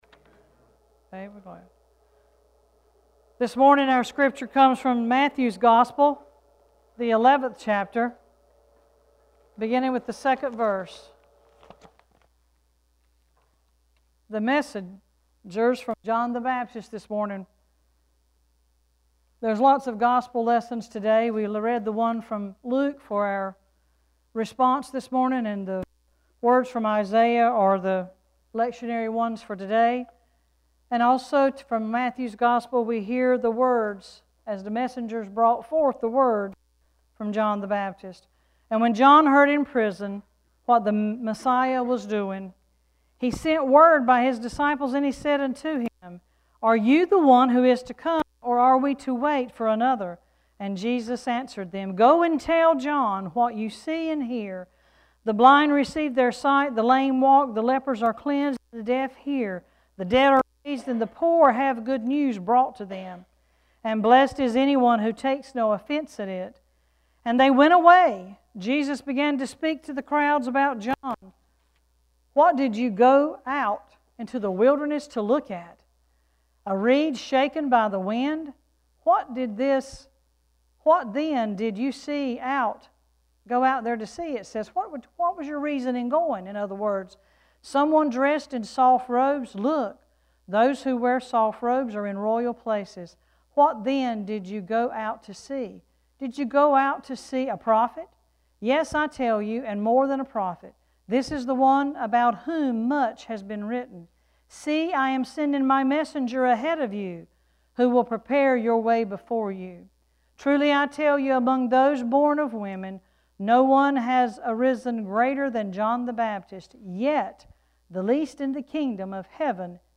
Worship Service 12-11-16: “Where Can I Find God”